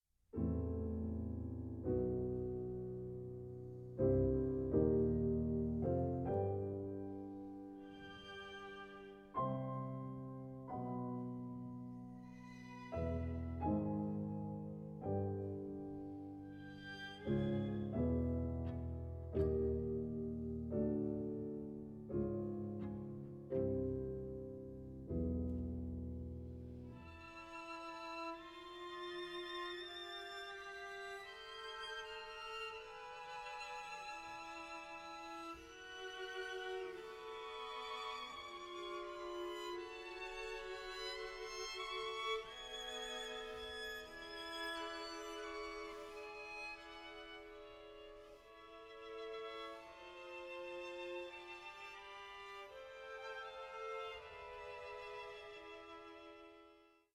violin
viola
cello
hymn